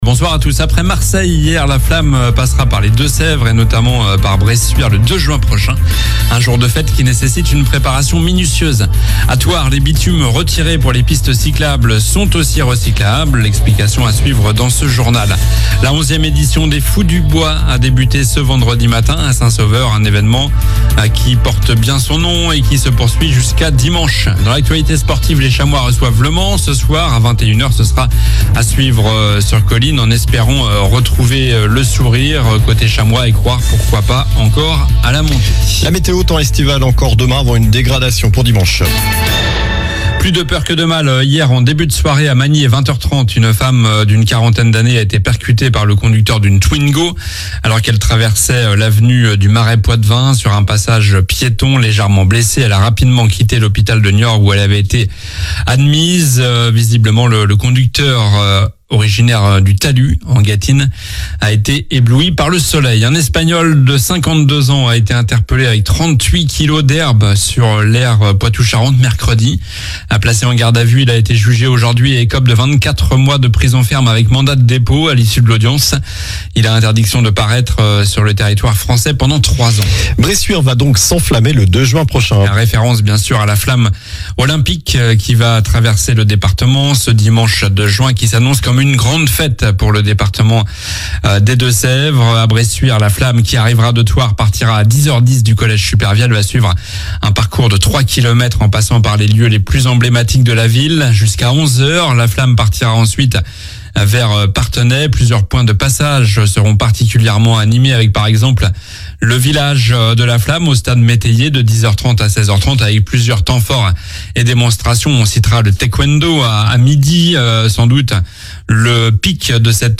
Journal du vendredi 10 mai (soir)